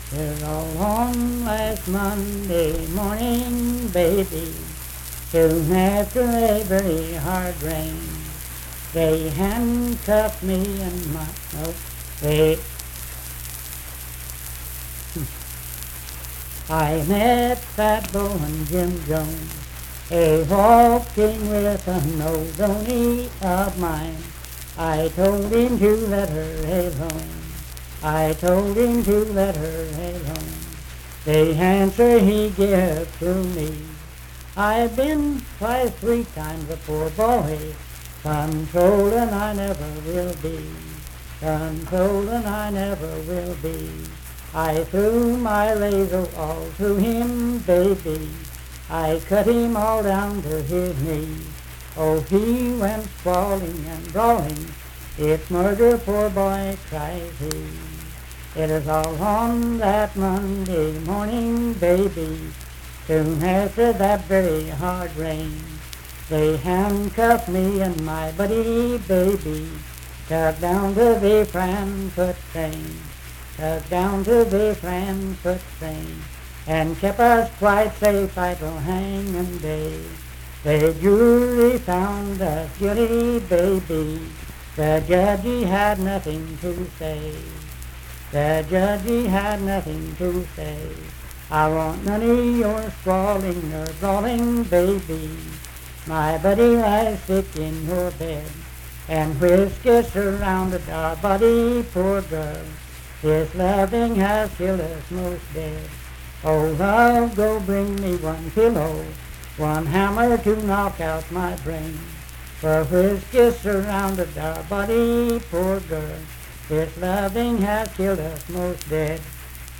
Unaccompanied vocal music performance
Verse-refrain 13(2).
Voice (sung)